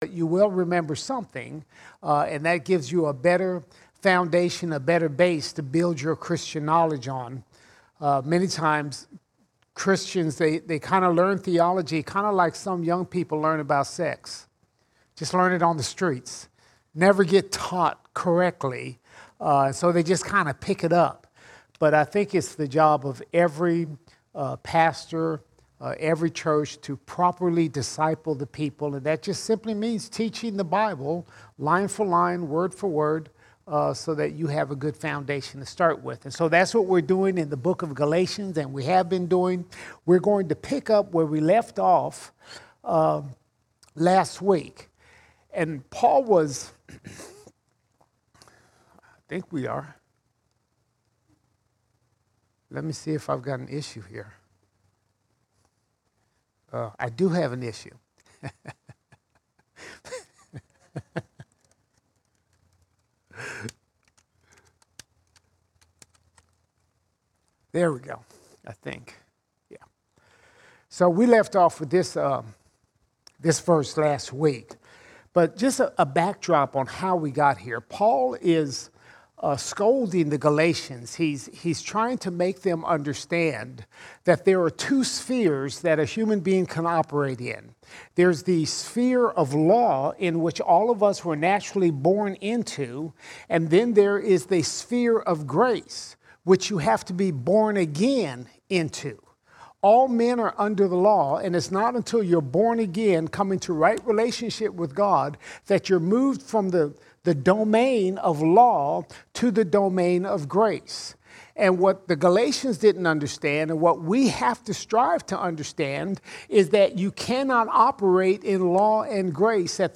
9 June 2022 Series: Galatians All Sermons Galatians 4:5 to 4:29 Galatians 4:5 to 4:29 We discover being under Law is a slavery we are born into.